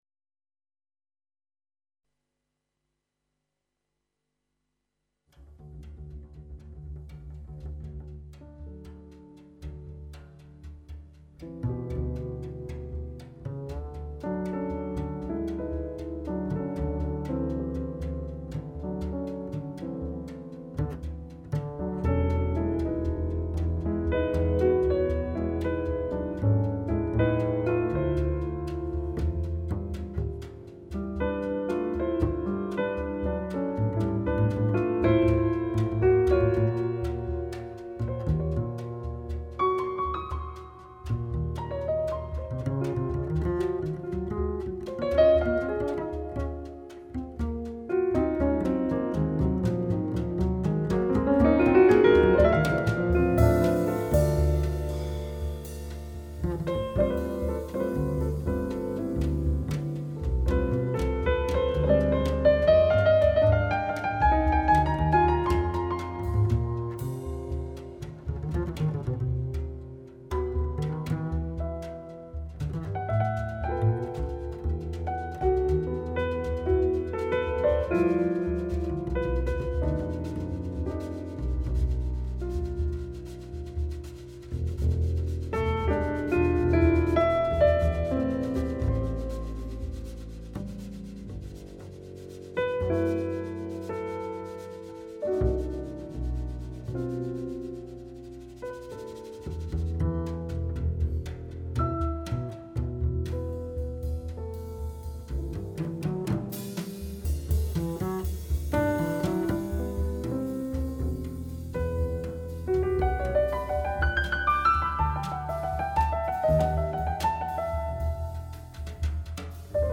batterie
contrebasse
piano
Jazz